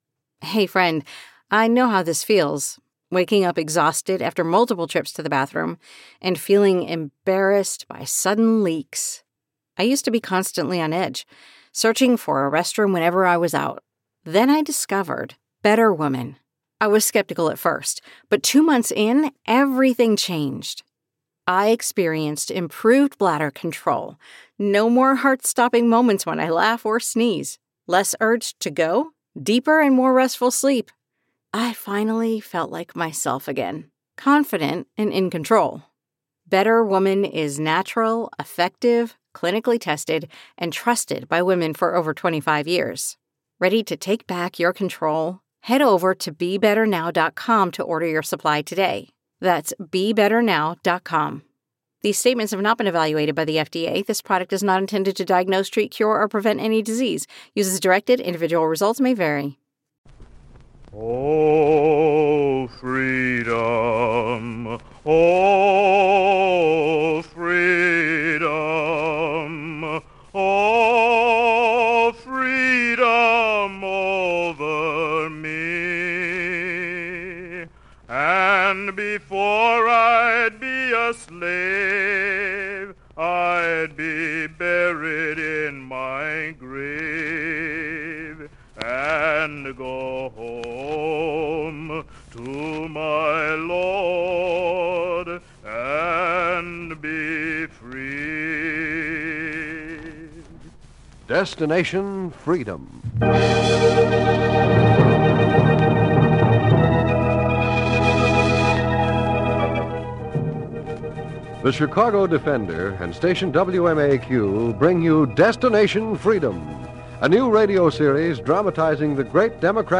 This 1948 broadcast delves into the life of Sojourner Truth, an extraordinary woman who rose from the shackles of slavery to become a formidable advocate for abolition and women's rights. In this episode, the narrative captures Sojourner's relentless pursuit of justice as she takes her message straight to the nation's capital.
The dramatization brings to life her famous speeches and the profound effect she had on both the abolitionist movement and the fight for women's suffrage.